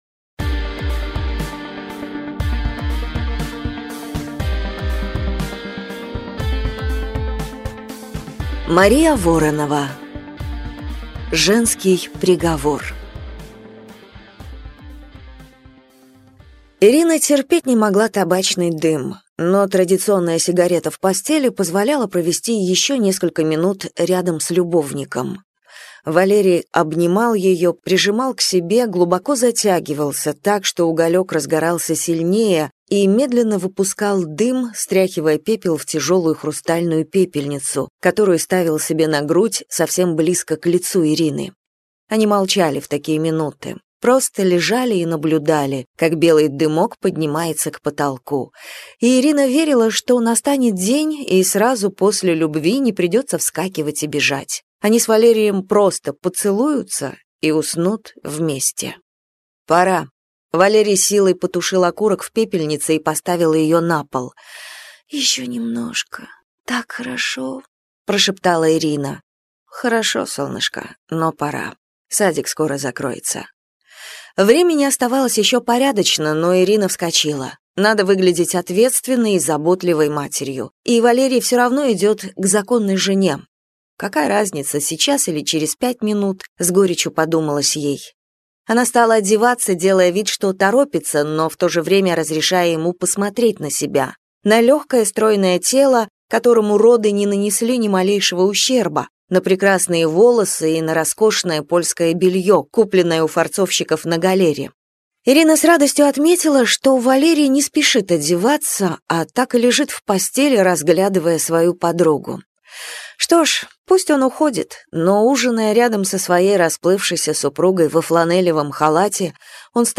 Аудиокнига Женский приговор | Библиотека аудиокниг